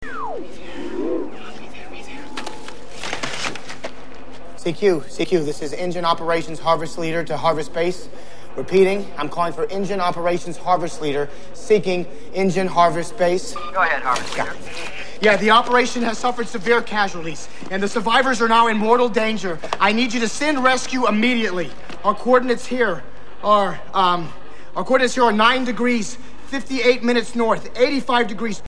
As he rotates the tuning knob to bring it to the expedition  frequency, the characteristic Single Sideband Modulation (SSB) tuning audio is heard. He then picks up the desktop  microphone and give a CQ CQ call to InGen HQ, giving his position information in Longitude & latitude.